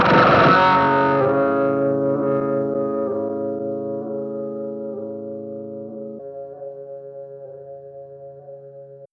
10 Boiling In Dust Noisy Guitar Fade.wav